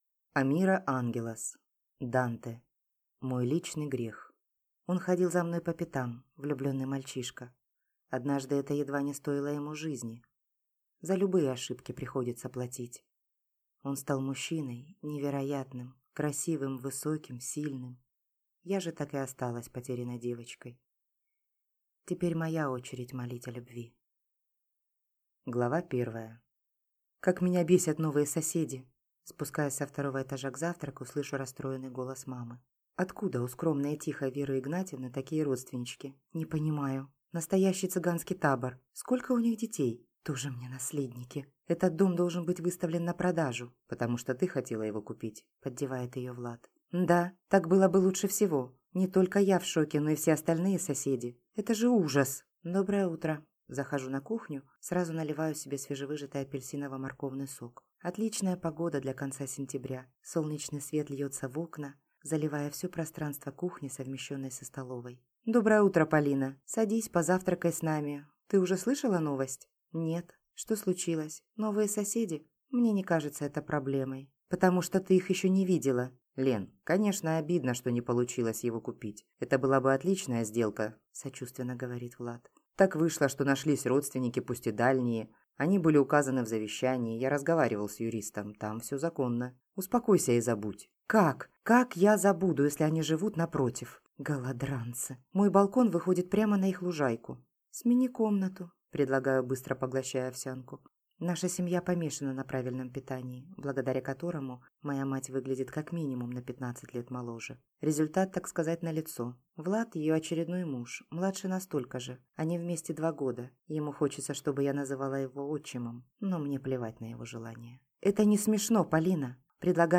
Аудиокнига Данте. Мой личный Грех | Библиотека аудиокниг